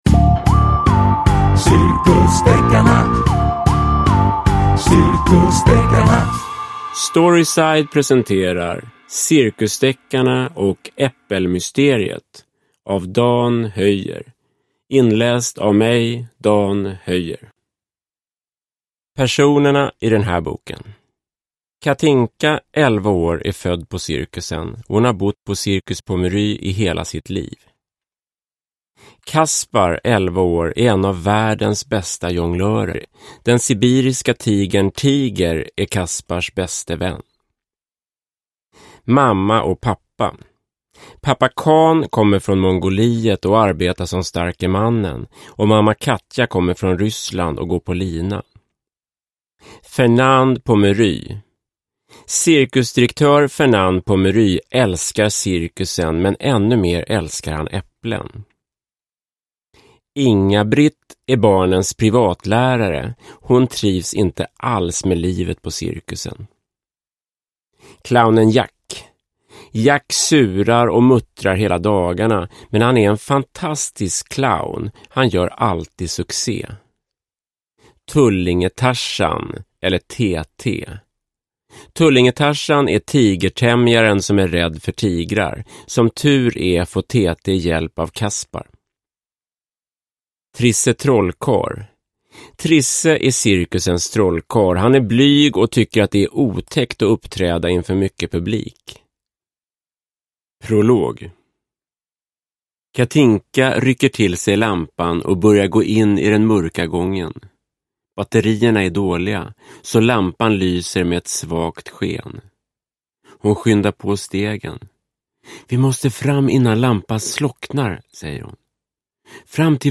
Cirkusdeckarna och äppelmysteriet – Ljudbok – Laddas ner